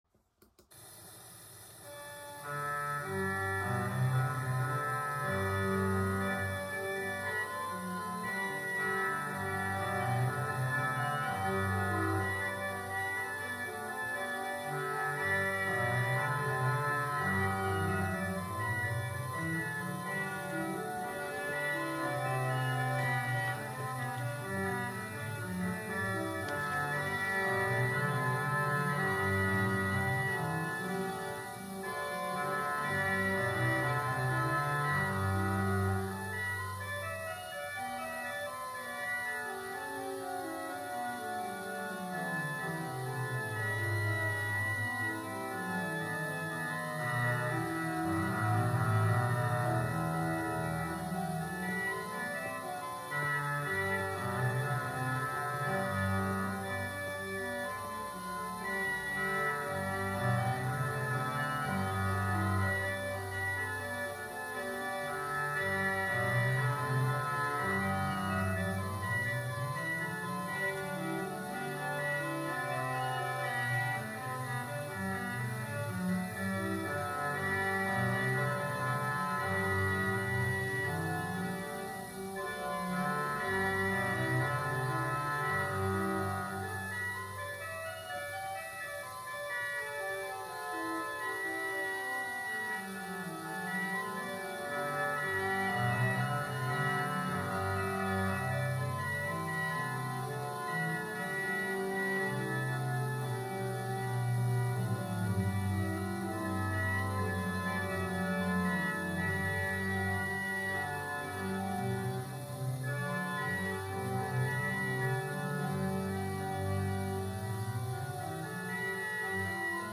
1931, disque 78 tours, 30 cm, Columbia DFX 233, Jean-Sébastien Bach :